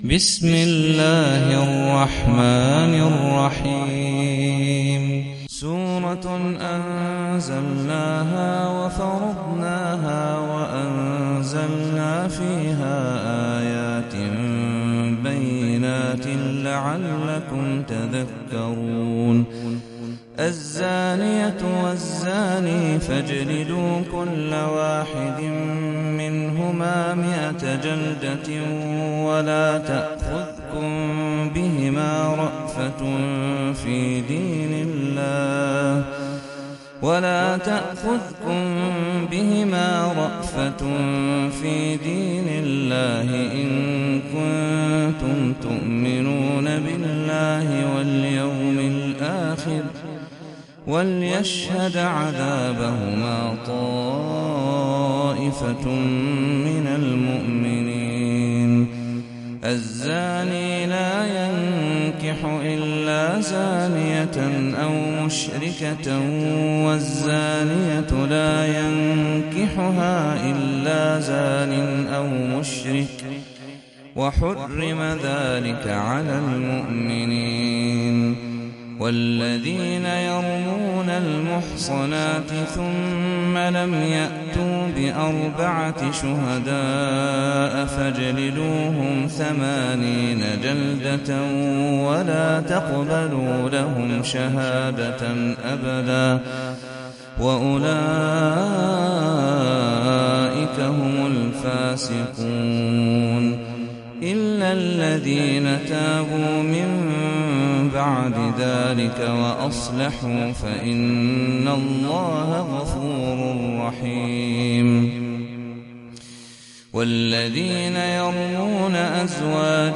Sûrat An-Noor (The Light) - صلاة التراويح 1446 هـ (Narrated by Hafs from 'Aasem)